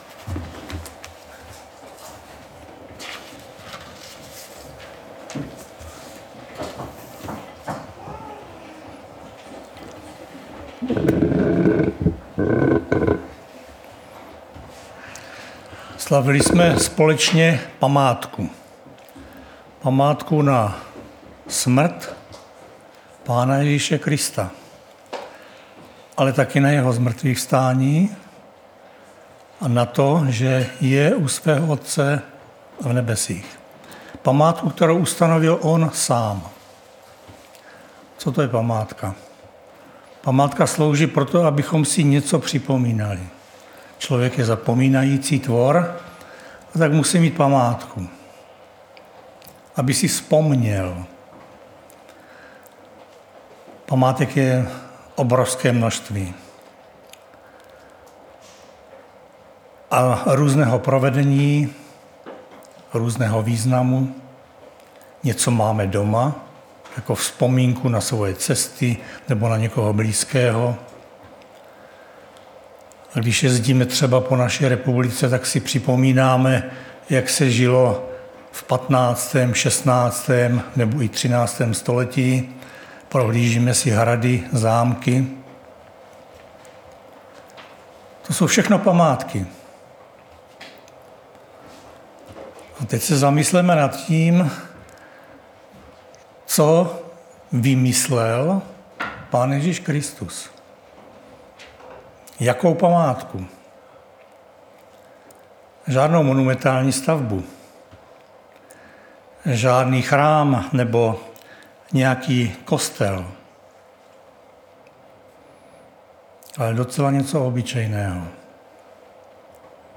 Nedělní vyučování